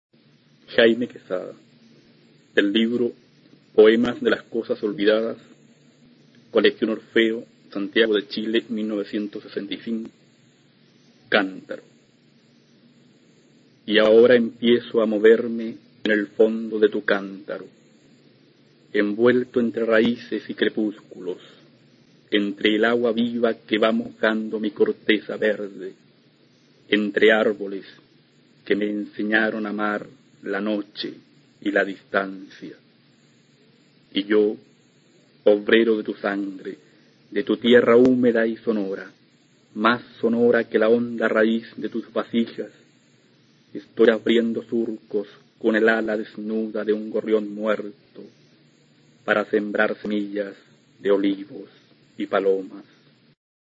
Poesía
Poema